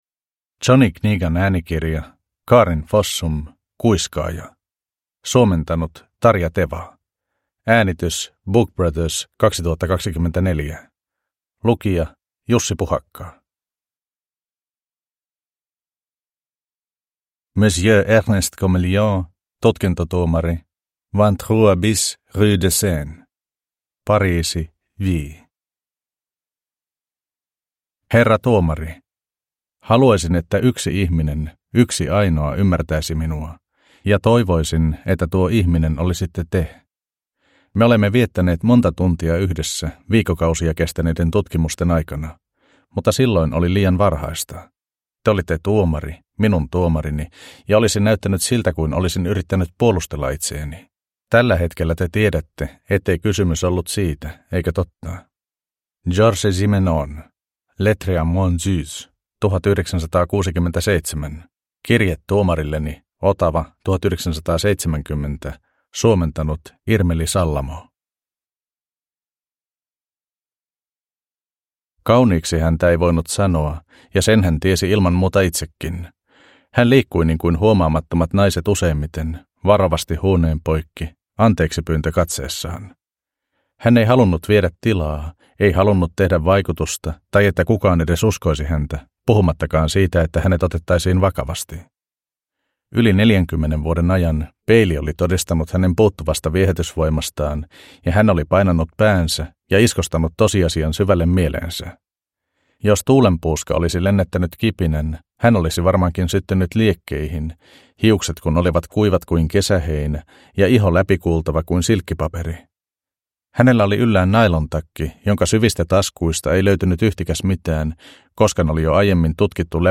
Kuiskaaja – Ljudbok